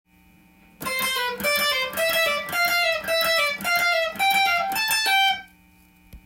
Gミクソリディアンスケールを例にフレーズを作ってみました。
①のフレーズは1つ弦でハイポジションに少しづつ
上昇していく形です。